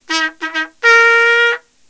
khanat-sounds-sources/_stock/sound_library/sfx/trompets/attention.wav at main